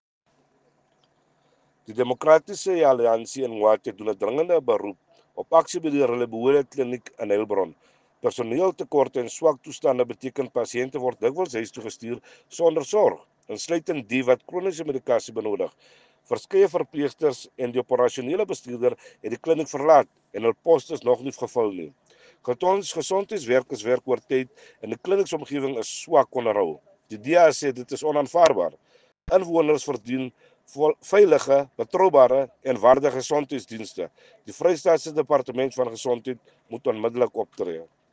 Afrikaans soundbites by Cllr Robert Ferendale and